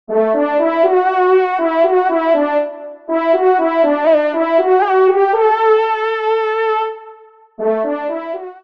FANFARE
Extrait de l’audio « Ton de Vènerie »